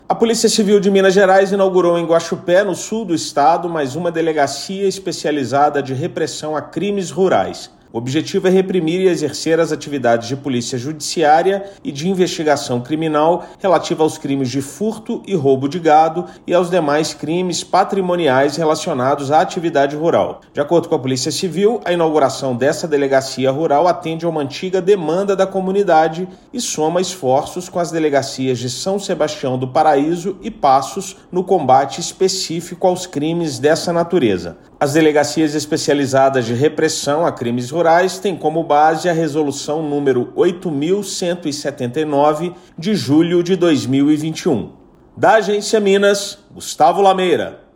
Objetivo é reprimir e investigar crimes patrimoniais relacionados à atividade rural, como furtos e roubos de gado. Ouça matéria de rádio.